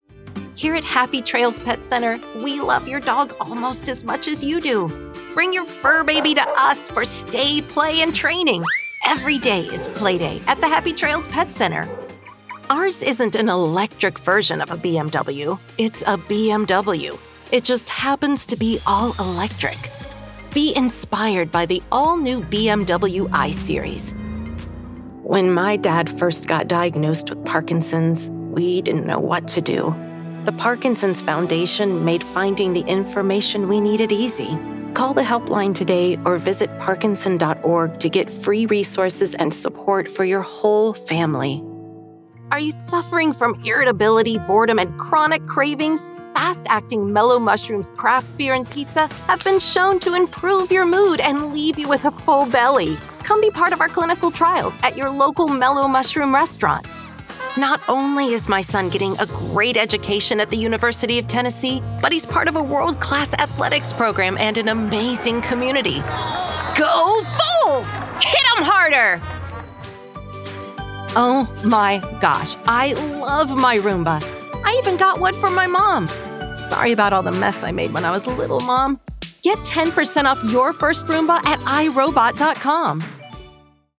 Female Voice Talent